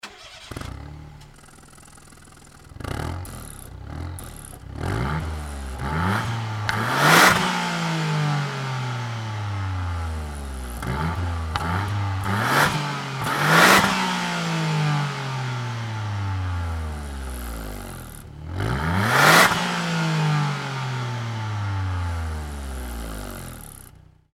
GTbox06&S汎用サイレンサー（空吹かし）
汎用サイレンサーでは虫の鳴き声のような
高音の不快なビビり音が入っていますが
copen_la400-kakimoto_general_baffle_acceleration.mp3